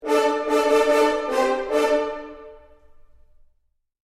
Trumpet Medieval Efeito Sonoro: Soundboard Botão
Trumpet Medieval Botão de Som